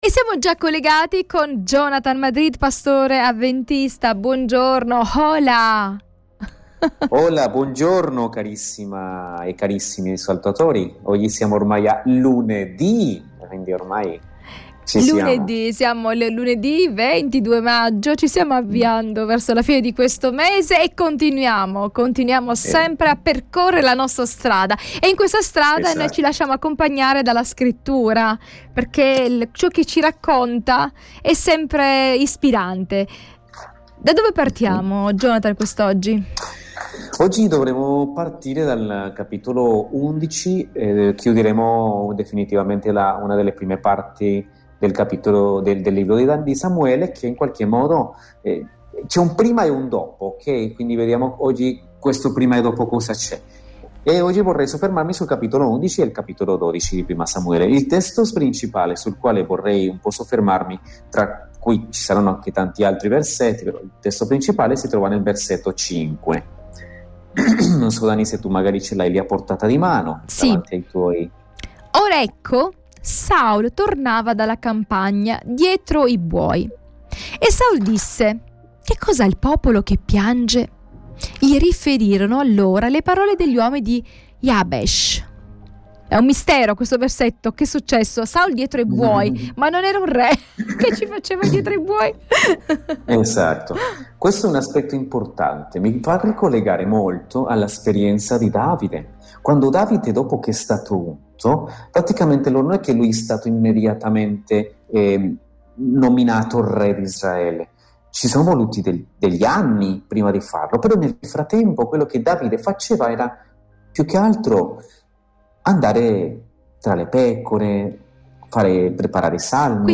pastore avventista.